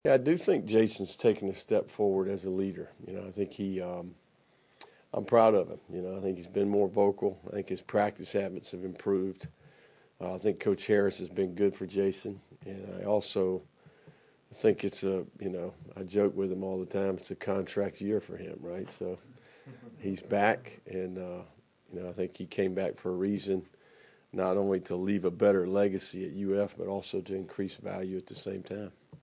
Florida football coach Billy Napier previewed the Week 1 matchup against No. 19 Miami during a news conference Monday.